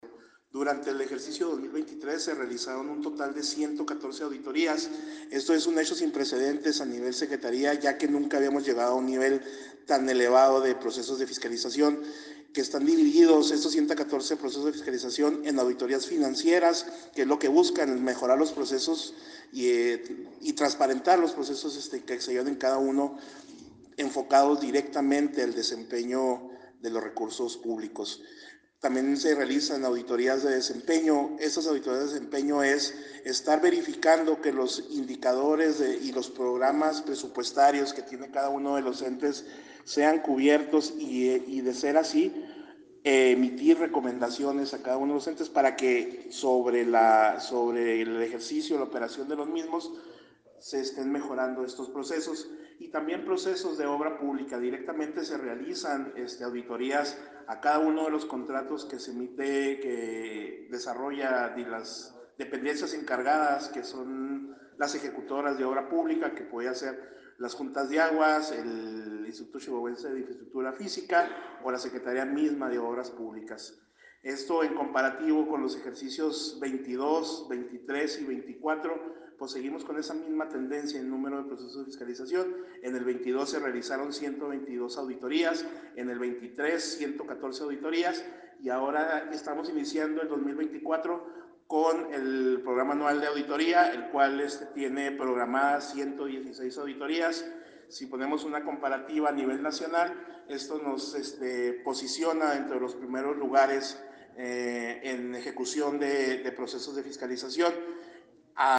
AUDIO: OSIEL TORRES, TITULAR DE LA SUBSECRETARÍA DE FISCALIZACIÓN DE LA SECRETARÍA DE LA FUNCIÓN PÚBLICA (SFP)